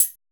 TAMBO CHH 2.wav